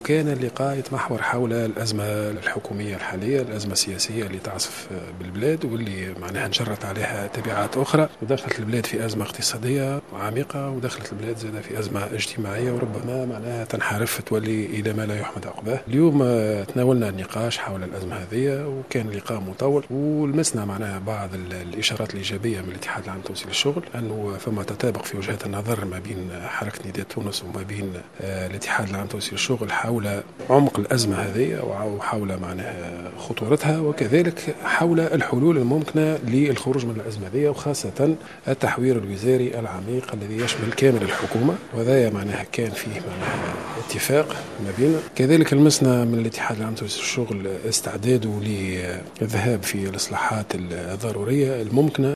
أكّد المتحدث باسم نداء تونس منجي الحرباوي في تصريح  إعلامي أن قيادات من حركة نداء تونس على رأسهم المدير التنفيذي حافظ قائد السبسي والأمين العام لإتحاد الشغل نور الدين الطبوبي اتفقوا خلال لقاء جمعهم اليوم على ضرورة تغيير كامل أعضاء الحكومة الحالية حتى تتمكن البلاد من الخروج من الأزمة التى تعيشها .